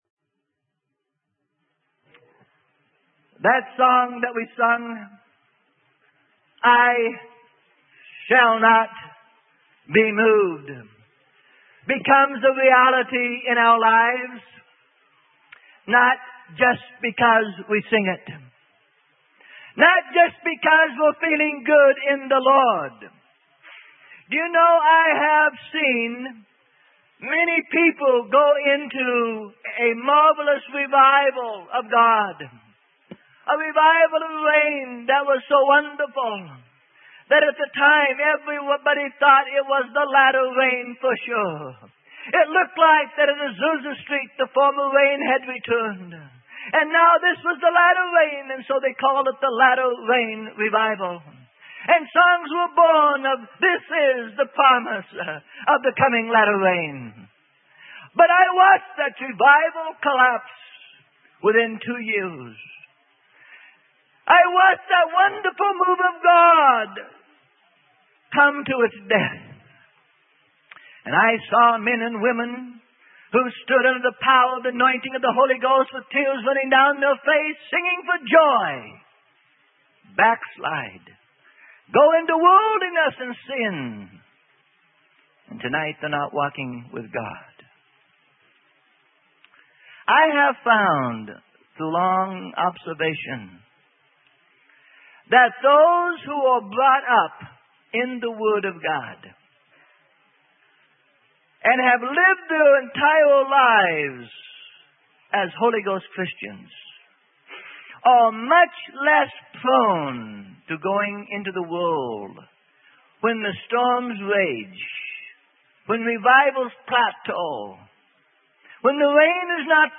Sermon: Unity Only by Commitment - Freely Given Online Library